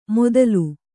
♪ modalu